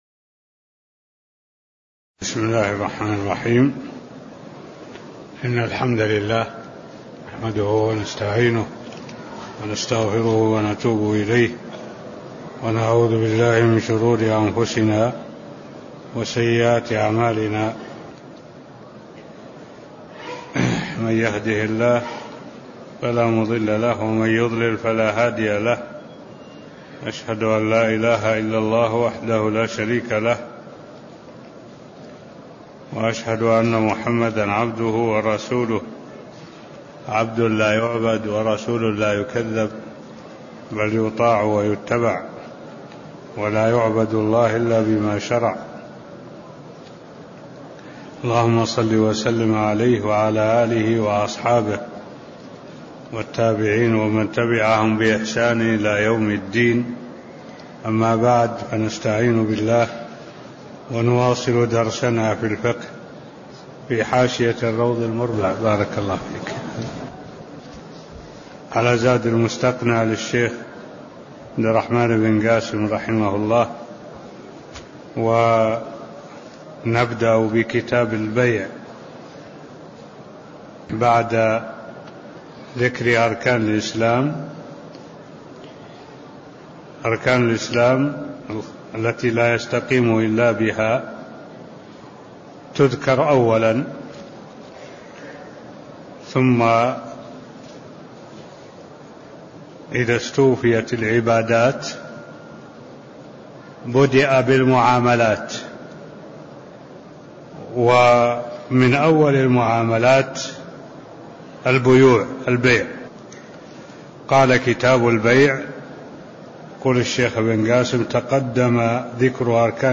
تاريخ النشر ١٣ جمادى الآخرة ١٤٢٧ هـ المكان: المسجد النبوي الشيخ: معالي الشيخ الدكتور صالح بن عبد الله العبود معالي الشيخ الدكتور صالح بن عبد الله العبود مقدمة كتاب البيوع (حكمه) (001)